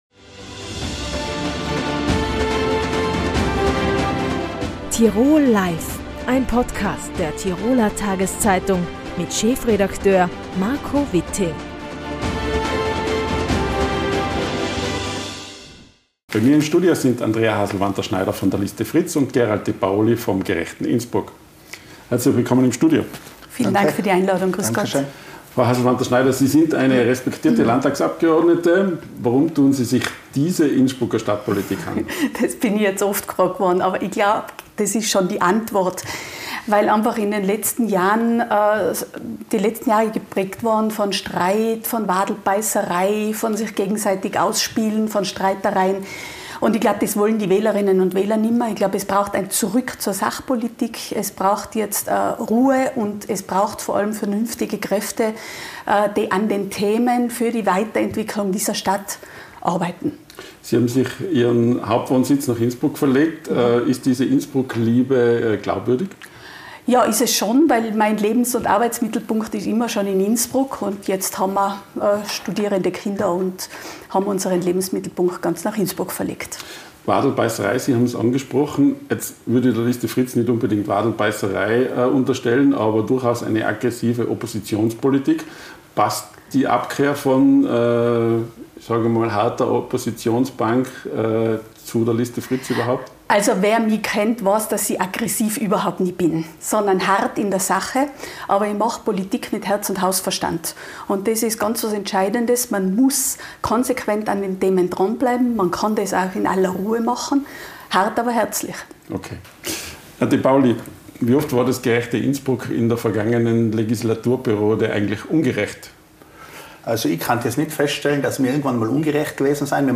Der Wahlkampf vor der Gemeinderatswahl in Innsbruck geht in die heiße Phase. Im ersten TT-Wahlduell standen sich die Bürgermeisterkandidaten Andrea Haselwanter-Schneider (Liste Fritz) und Gerald Depaoli (Gerechtes Innsbruck) gegenüber.